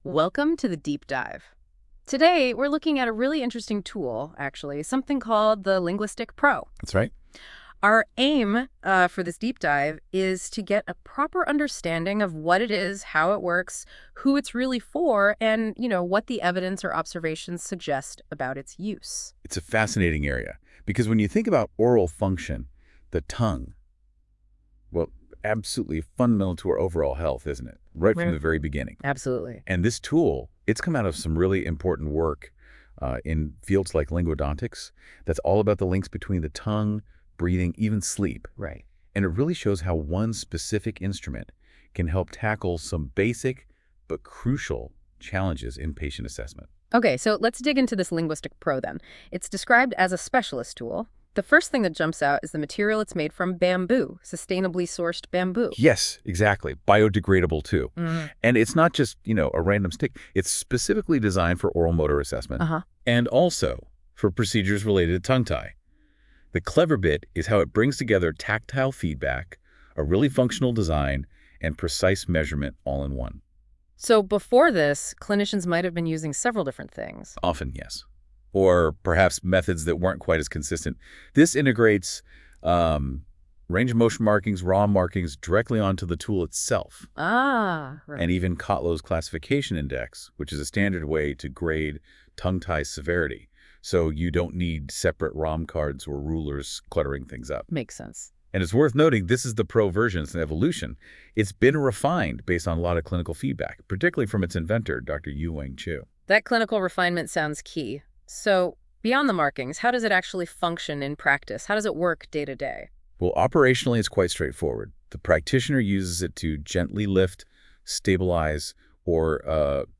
This short audio explainer delivers trusted, evidence-based insights in an easy-to-listen format. While the voice has been generated using AI technology, all content has been carefully written, reviewed, and verified by the Breathe First clinical team for accuracy.